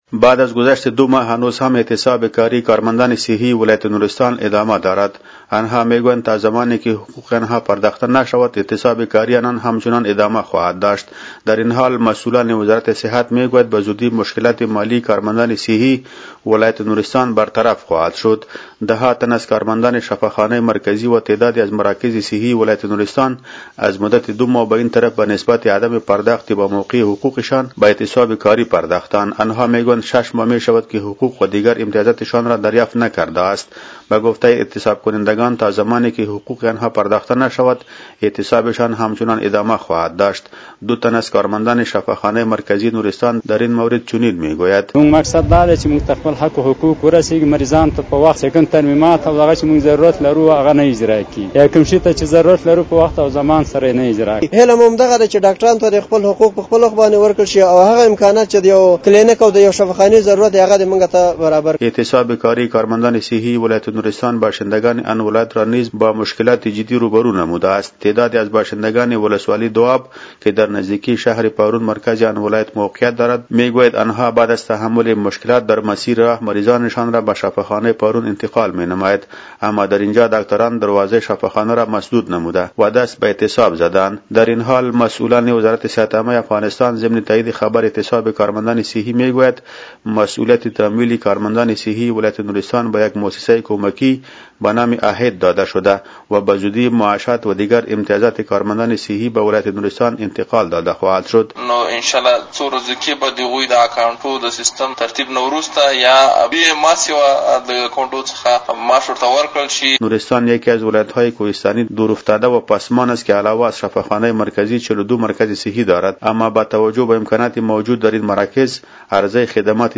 به گزارش خبرنگار رادیو دری، بعد از گذشت دوماه هنوز هم اعتصاب کاري کارمندان صحي ولایت نورستان ادامه دارد انها میگویند تا زمانیکه حقوق انها پرداخته نشود اعتصاب کاري انان همچنان ادامه خواهد داشت.